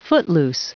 Prononciation du mot footloose en anglais (fichier audio)
Prononciation du mot : footloose